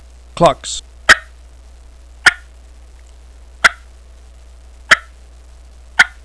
Yellow Yelper 3 Reed, 3 Cutt Mouth Call
Listen to 6 seconds of clucks
yythreethreeclucks6.wav